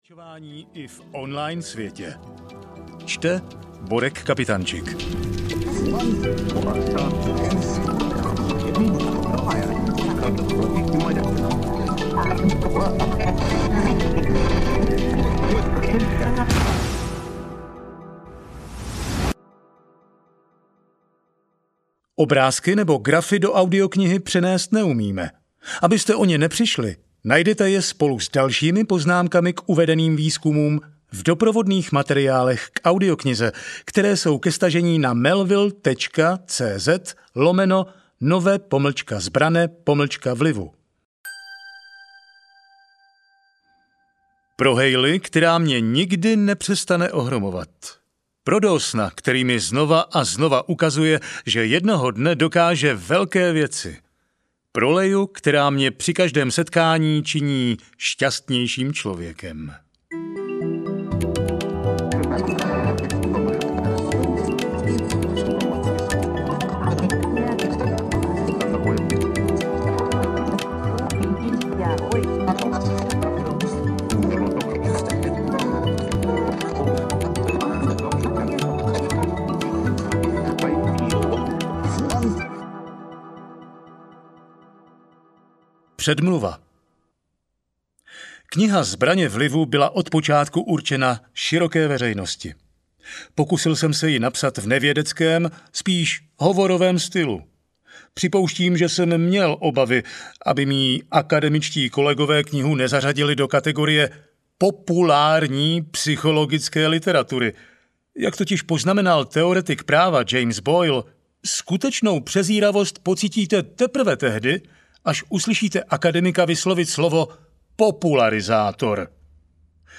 Nové zbraně vlivu audiokniha
Ukázka z knihy